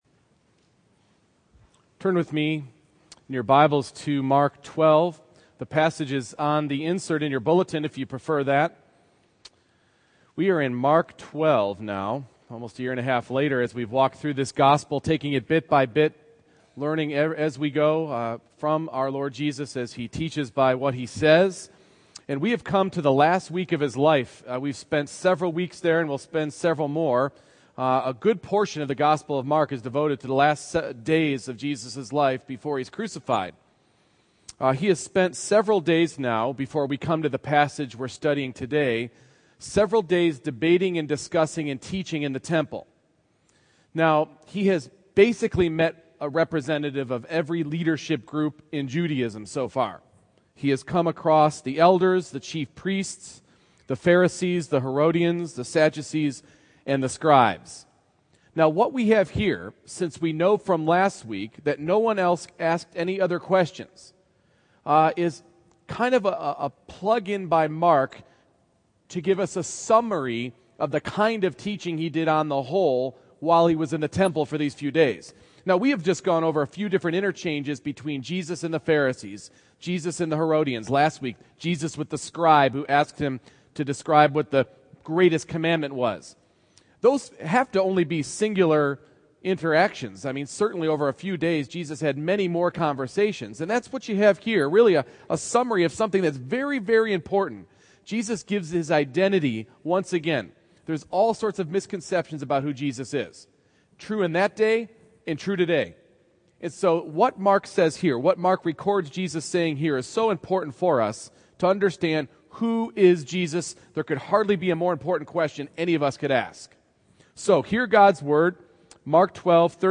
Mark 12:35-40 Service Type: Morning Worship It required a divine/human being to fulfill the Scriptural requirements for Messiah.